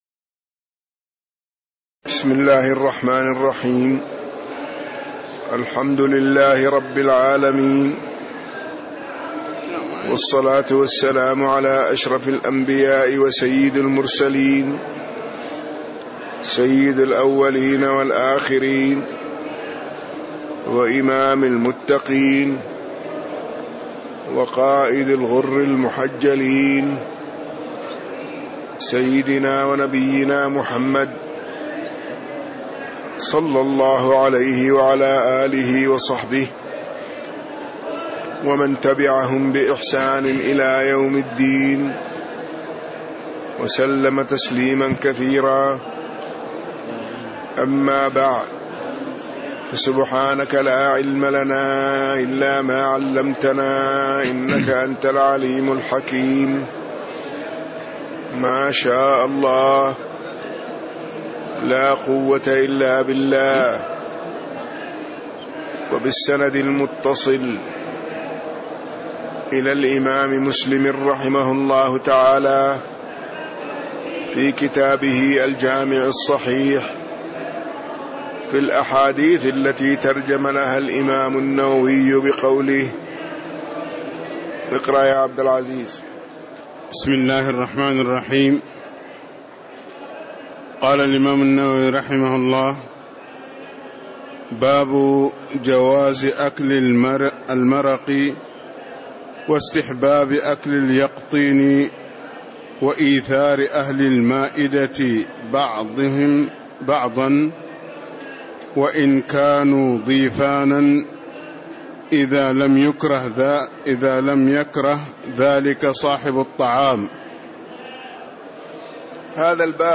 تاريخ النشر ٨ شعبان ١٤٣٦ هـ المكان: المسجد النبوي الشيخ